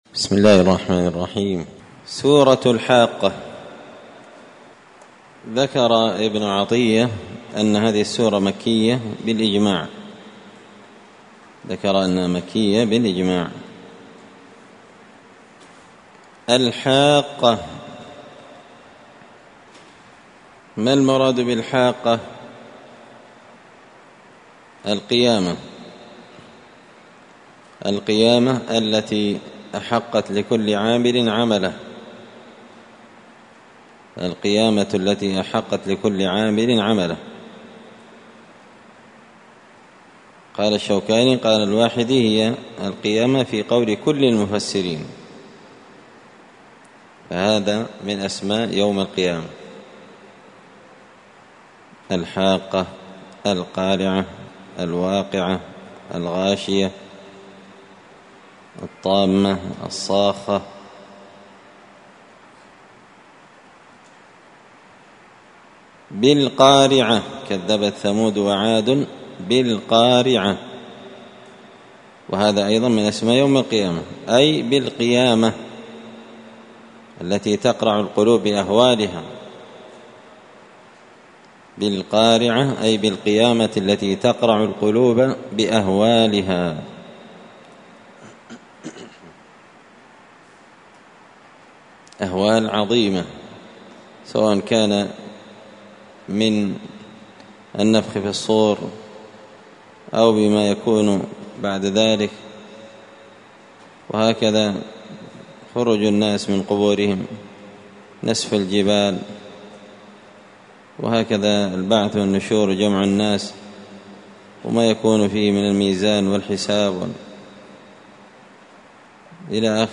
(جزء تبارك سورة الحاقة الدرس 69)